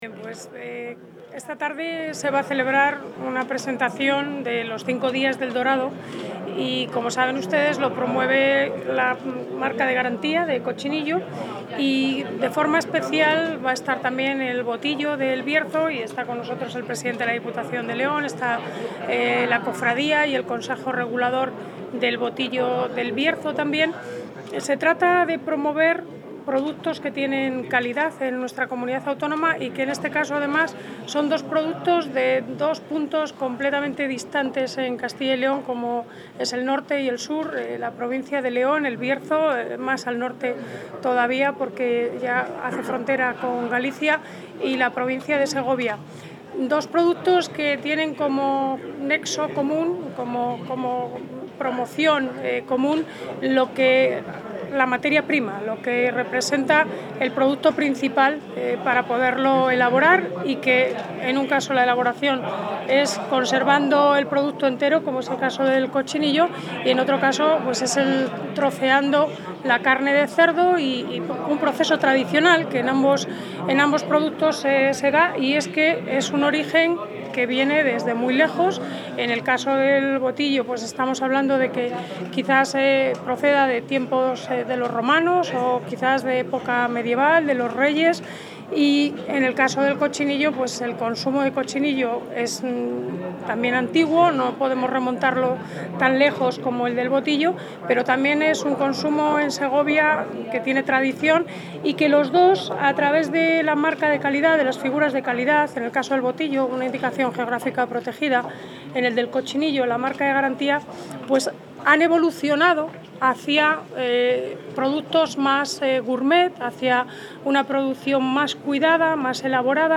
Audio consejera Agricultura y Ganadería.
La consejera de Agricultura y Ganadería, Silvia Clemente, ha presidido esta tarde, en la séptima edición del encuentro ‘5 Días de El Dorado’ de Segovia, el acto de hermanamiento entre las figuras de calidad de la Indicación Geográfica Protegida Botillo del Bierzo y la Marca de Garantía Cochinillo de Segovia. El valor económico conjunto de estas dos figuras de calidad supera los once millones de euros y su alianza permitirá generar sinergias que aumenten su valor añadido.